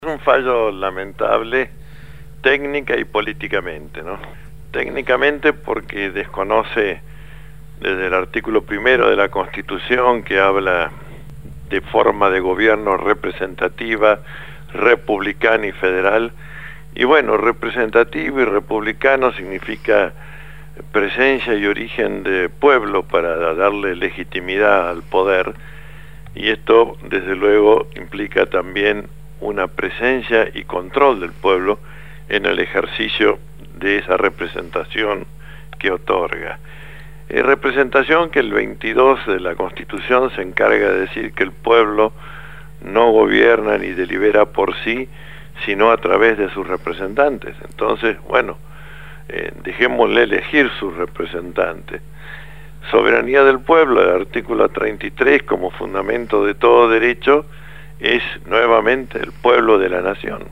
En diálogo telefónico